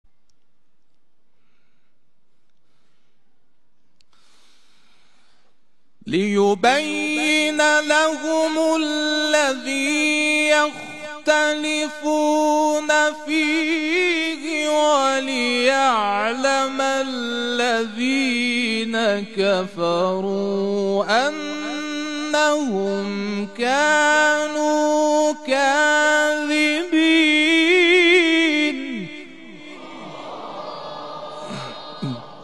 گروه فعالیت‌های قرآنی: جدیدترین مقاطع صوتی تلاوت شده توسط قاریان ممتاز کشور را می‌شنوید.
مقطع نهاوند